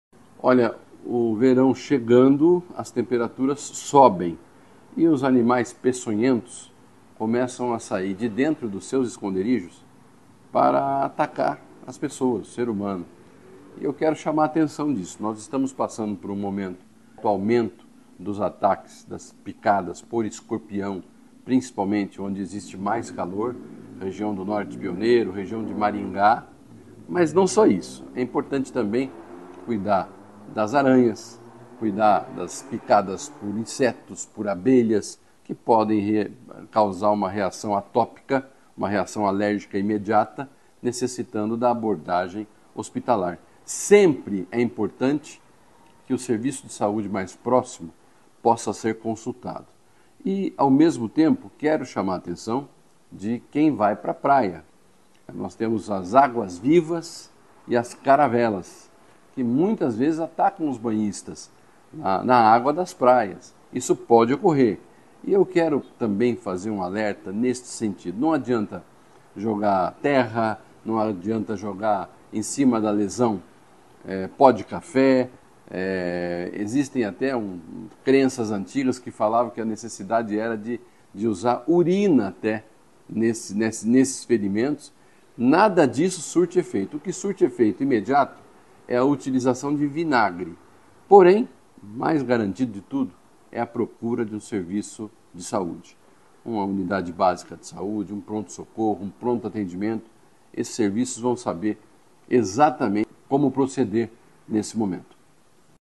Sonora do secretário da Saúde, Beto Preto, sobre o risco de acidentes com animais peçonhentos durante o verão